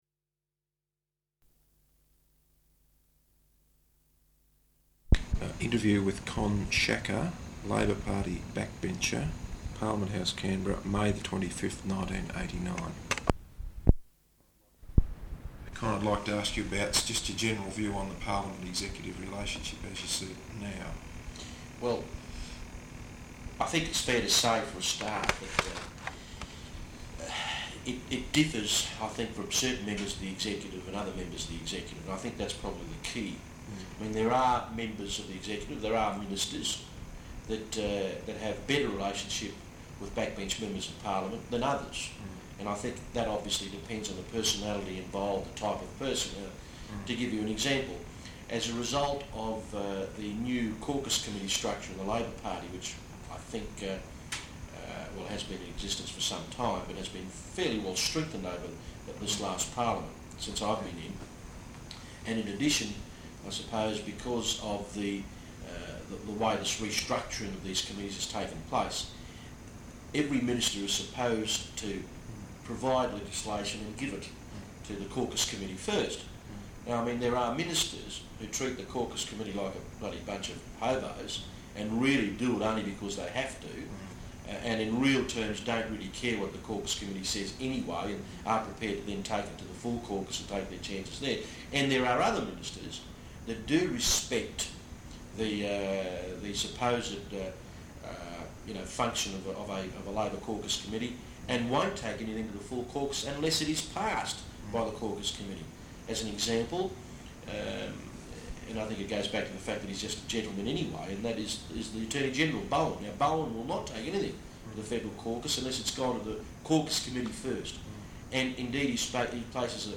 Interview with Con Sciacca, Labor Party Backbencher, Parliament House, Canberra May 25th 1989.